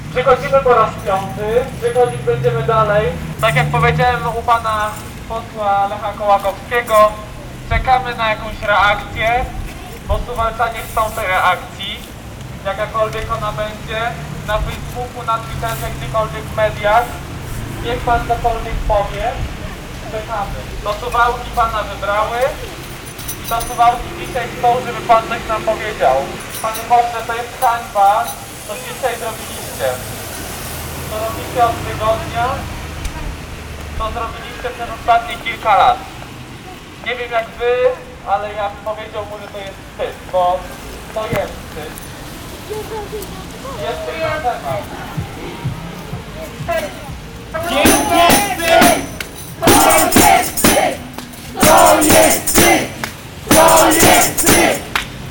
Pod biurach parlamentarzystów Prawa i Sprawiedliwości: Lecha Kołakowskiego i Jarosława Zielińskiego zapalone zostały znicze. Padły prośby o zabranie przez nich głosu w sprawie wyroku Trybunału – w jakikolwiek sposób, w jakimkolwiek medium.